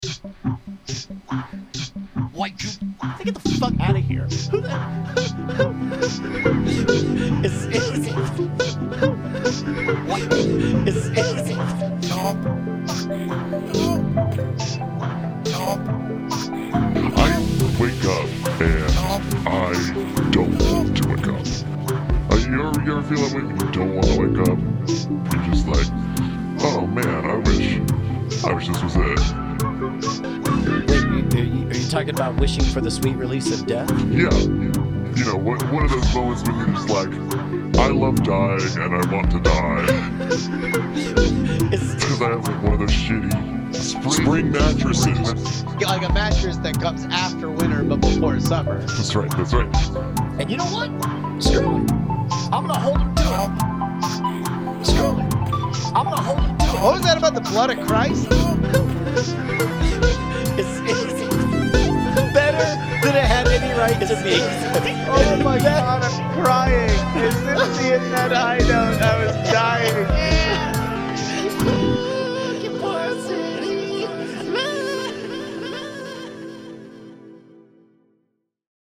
Dreamy Night Attack Songs